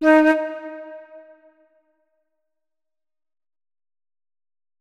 main Divergent / mods / Hideout Furniture / gamedata / sounds / interface / keyboard / flute / notes-39.ogg 46 KiB (Stored with Git LFS) Raw Permalink History